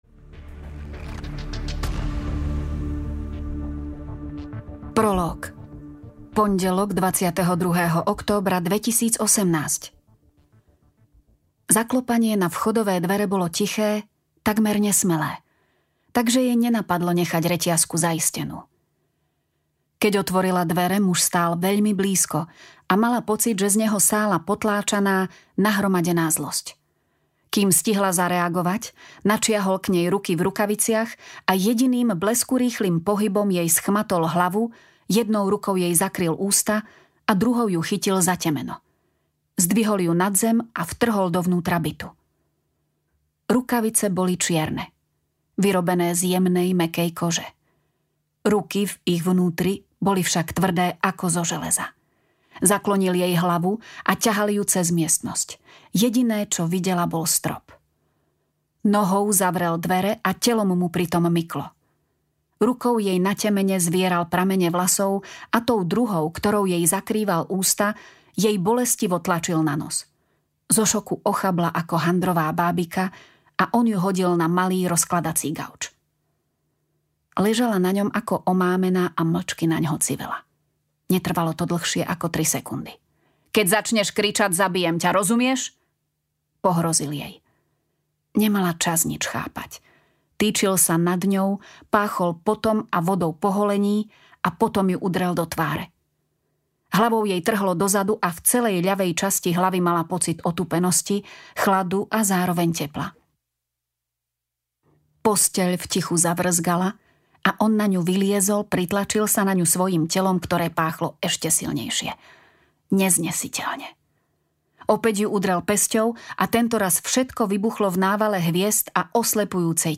Osudné svedectvo audiokniha
Ukázka z knihy